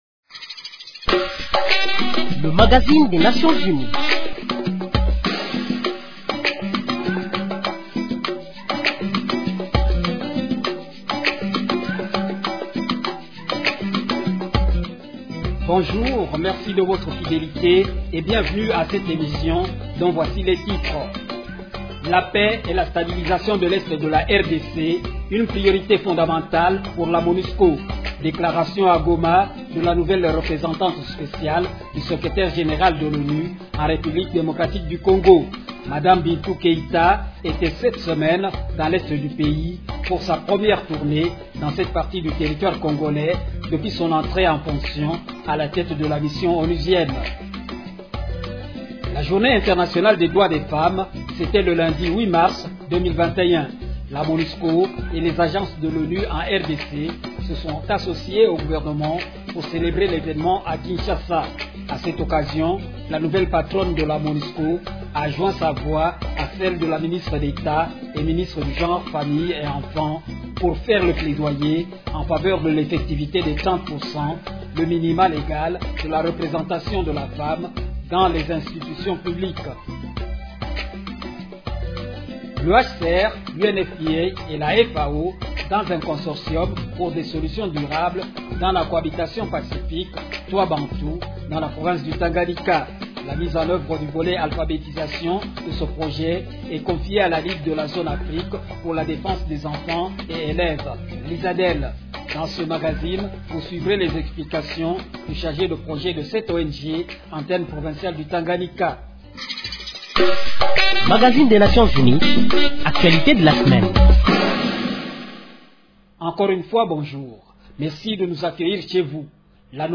Brèves *En plus bref, l’Organisation mondiale de la santé (OMS) a remis le 6 mars dernier des intrants à trois sites de traitement de Covid-19 de Matadi, au Kongo Central.